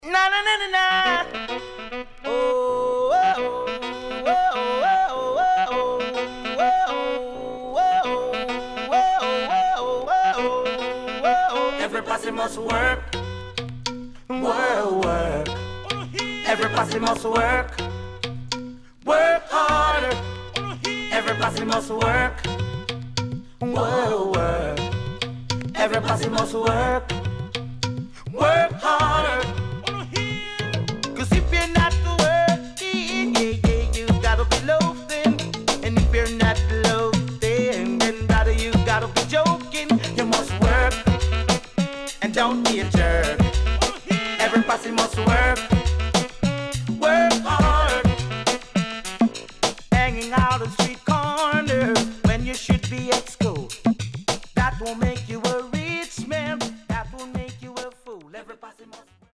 SIDE A:少しチリノイズ入りますが良好です。